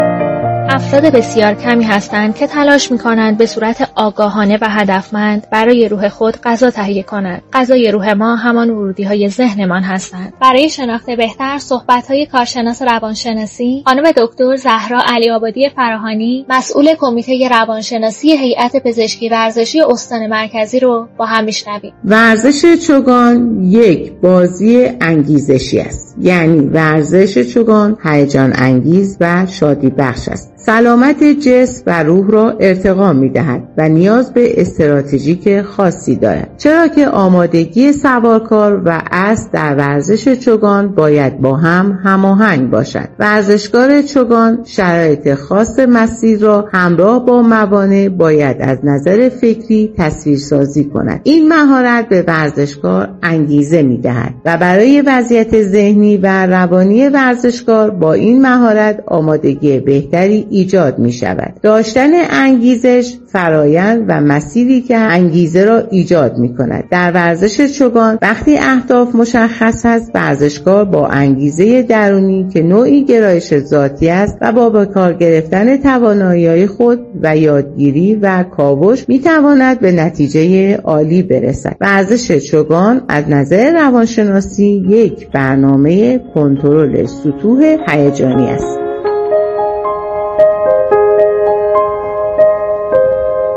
گفتگوی رادیویی